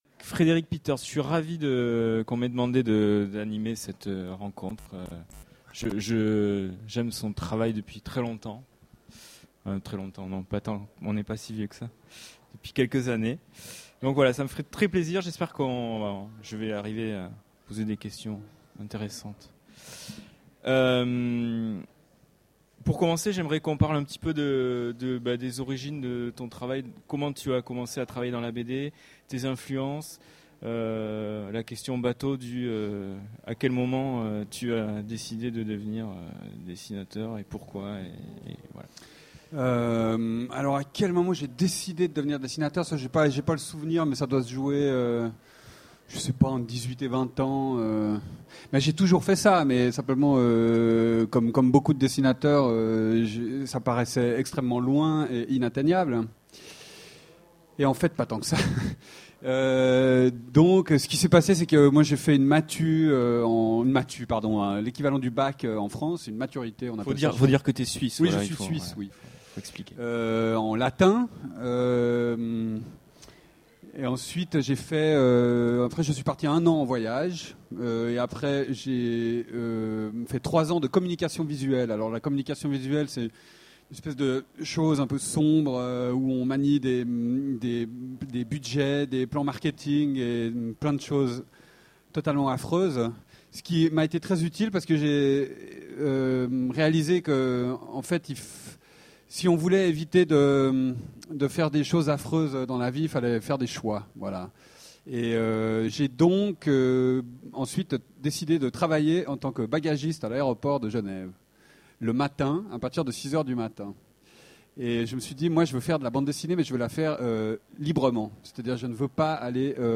Utopiales 13 : Conférence Rencontre avec Frederik Peeters
- le 31/10/2017 Partager Commenter Utopiales 13 : Conférence Rencontre avec Frederik Peeters Télécharger le MP3 à lire aussi Frederik Peeters Genres / Mots-clés Rencontre avec un auteur Conférence Partager cet article